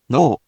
We’re going to show you the character, then you you can click the play button to hear QUIZBO™ sound it out for you.
In romaji, 「の」 is transliterated as 「no」which sounds like …well, the English or Spanish word「no」